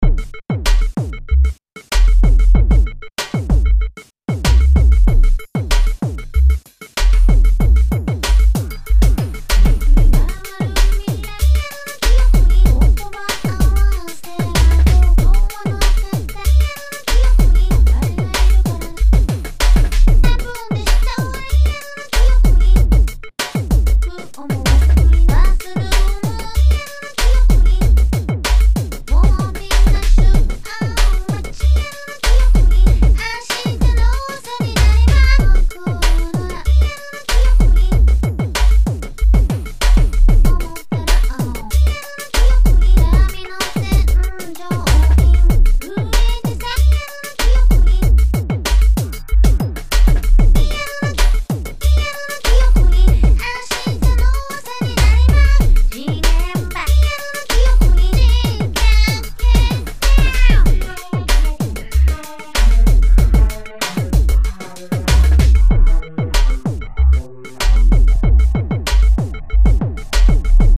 2003年07月製作：「リアルな記憶に」をリフレインしたくて。